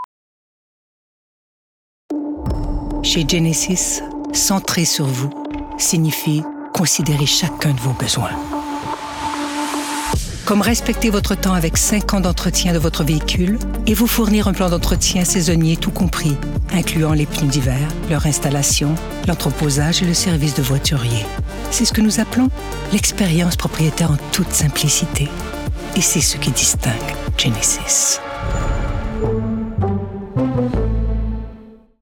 Comédienne
Démo de voix
45-60 ans ⸱ 60 ans et + ⸱ Publicité
Genesis (voix annonceur)